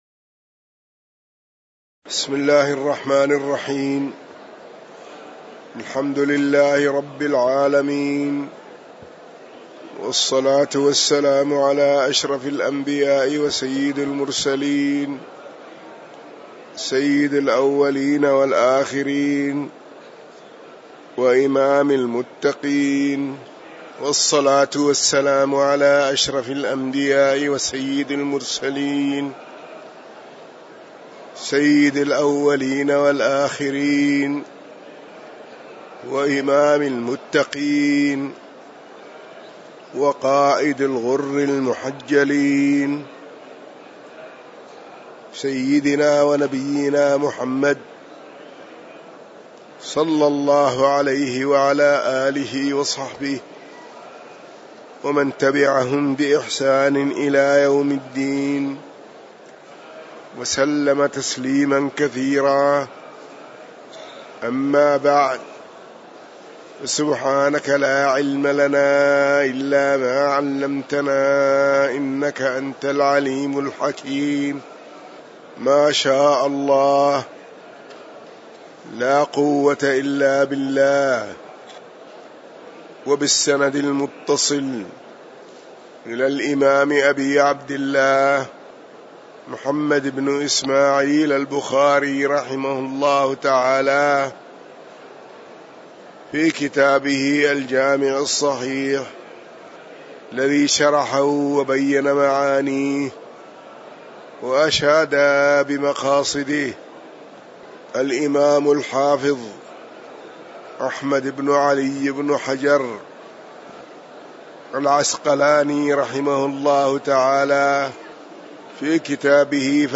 تاريخ النشر ٢٧ صفر ١٤٤٠ هـ المكان: المسجد النبوي الشيخ